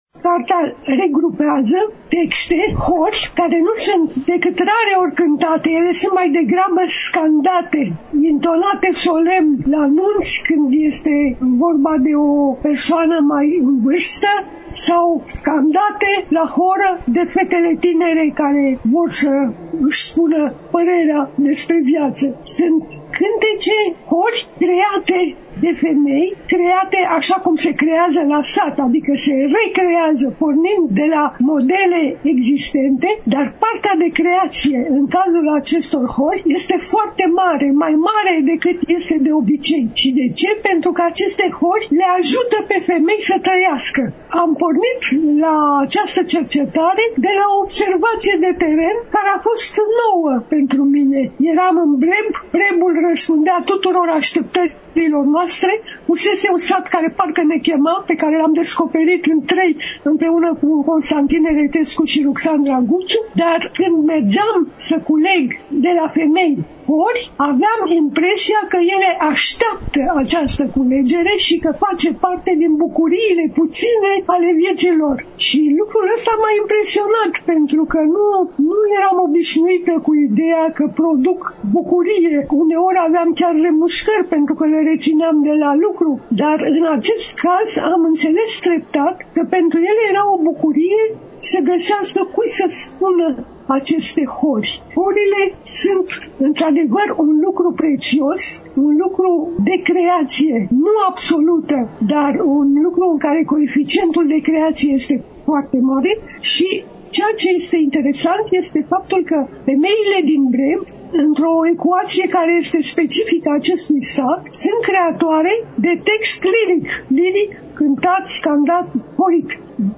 Astăzi, relatăm de la Târgul de Carte Gaudeamus Radio România, ediția a XXIX-a, 7 – 11 decembrie 2022, Pavilionul B2 al Complexului Expoziţional Romexpo, București.